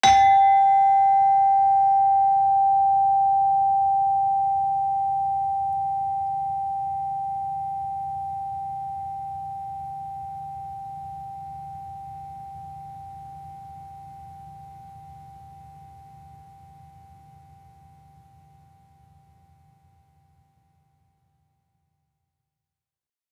Gender-4-G4-f.wav